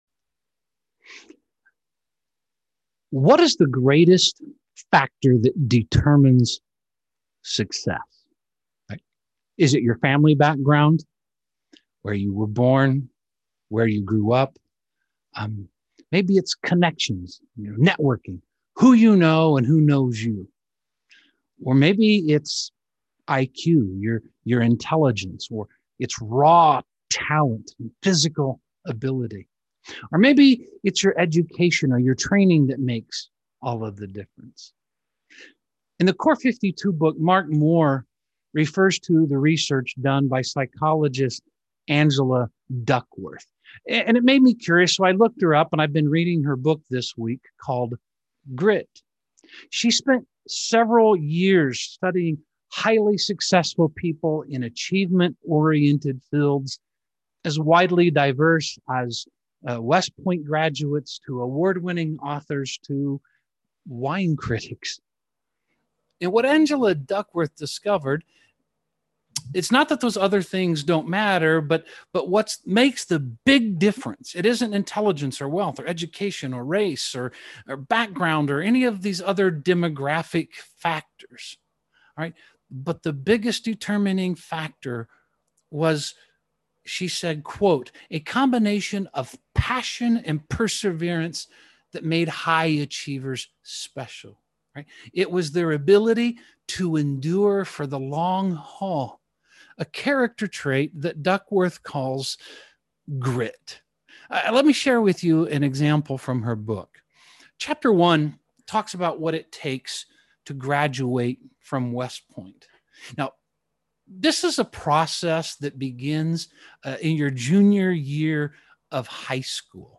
Perseverance Hebrews Core 52 Video Sermon Audio Sermon Text Sermon Save Audio Save PDF Following Jesus is a marathon